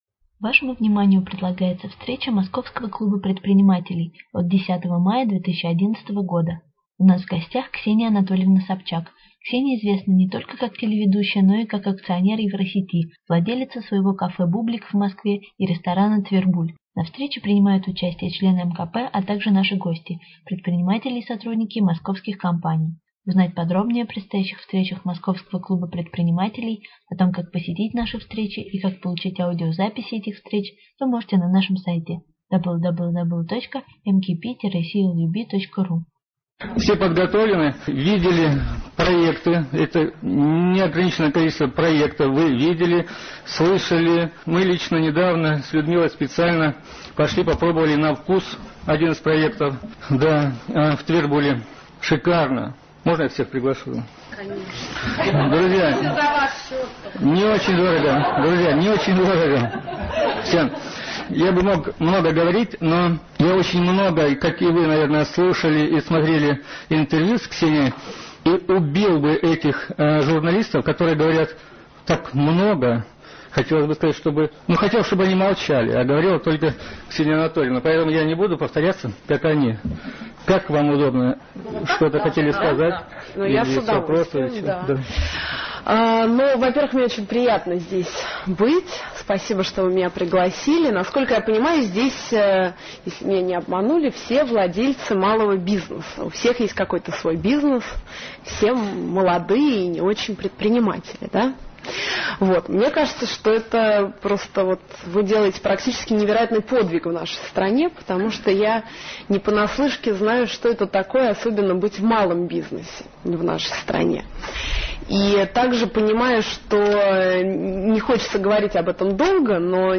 10 мая 2011 года с 19.00 до 22.00 прошла очередная ОТКРЫТАЯ встреча Московского клуба предпринимателей.
У нас в гостях побывала Ксения Анатольевна Собчак.